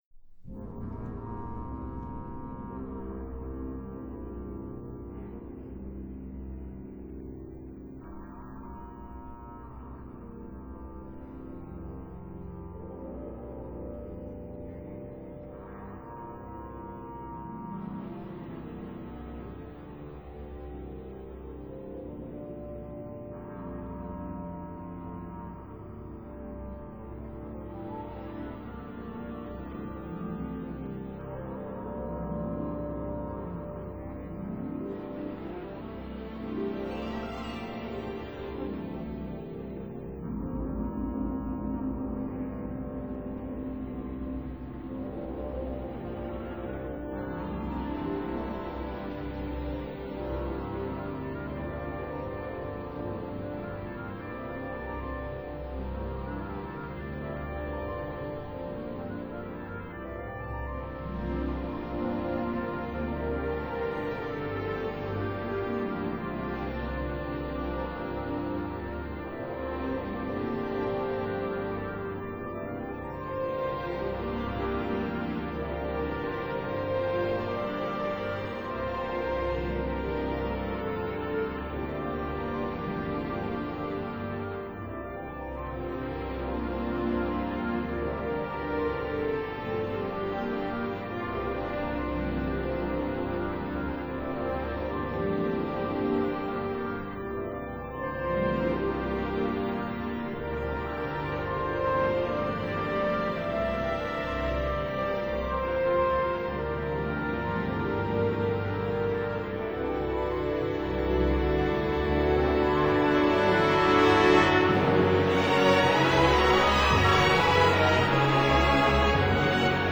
Symphony No. 5 'Percussion'